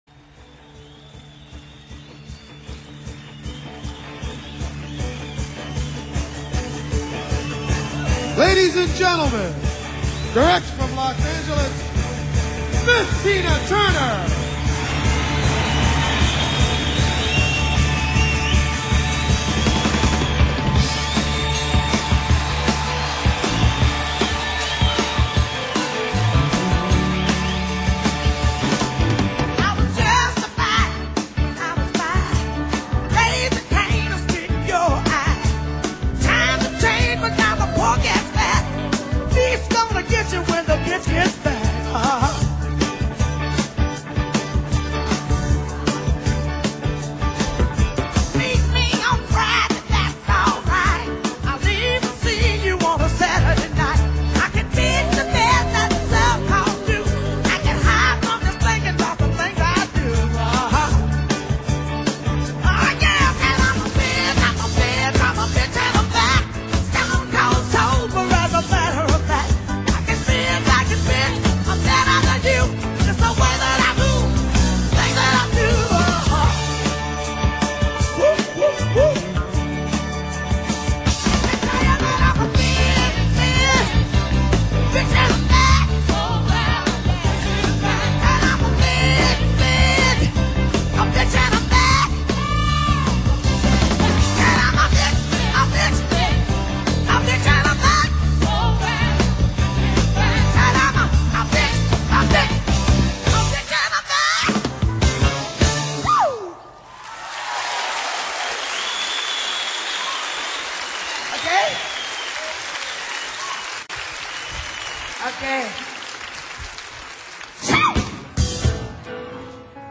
Carre, Amsterdam